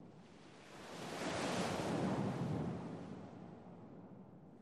Sports Wave Whoosh
Arena Crowd; Wave Whoosh For Crowd Transition.